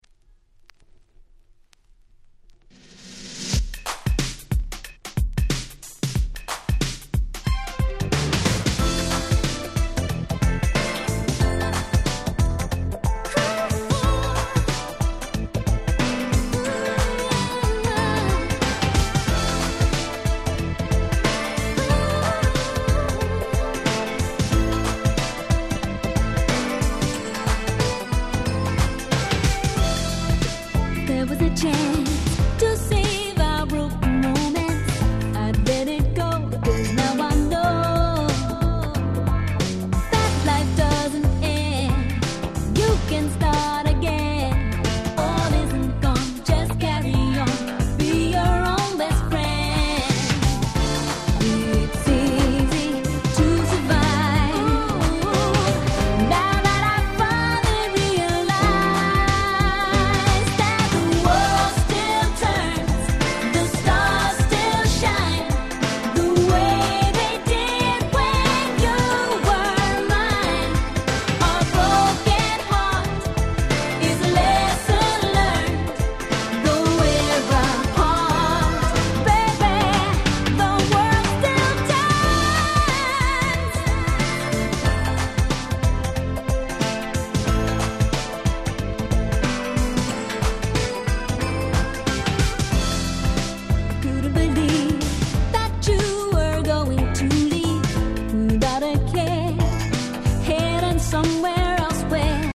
90' Nice R&B LP !!